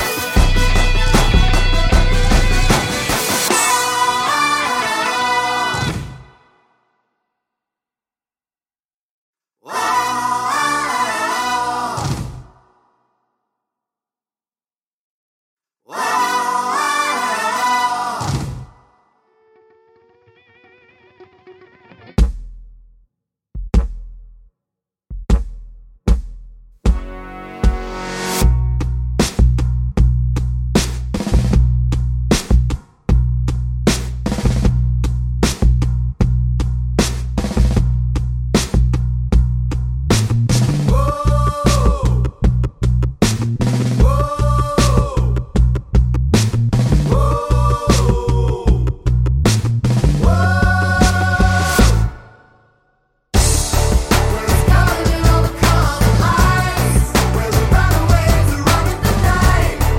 no Backing Vocals Musicals 5:02 Buy £1.50